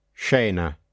SC wymawiamy jak /sz/w przypadku połączenia sci oraz sce: